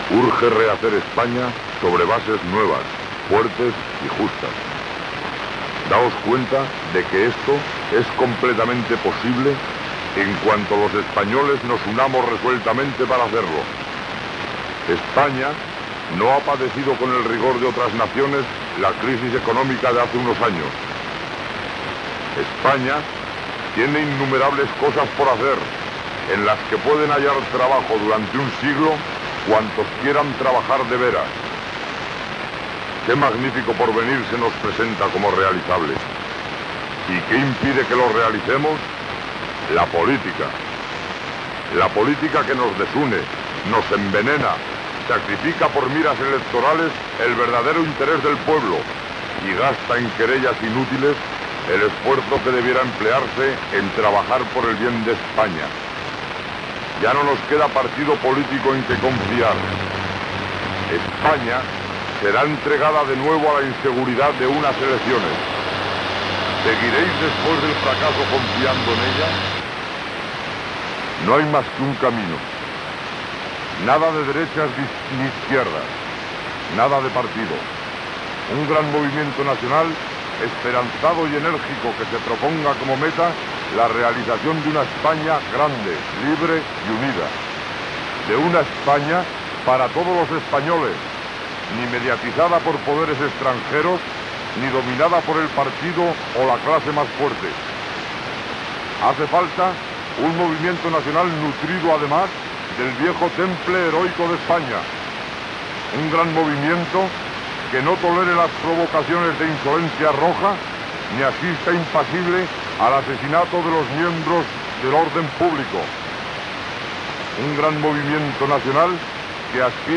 Opinió de la situació política espanyola basada en la doctrina Nacional Sindicalista, identificació i tema musical.
FM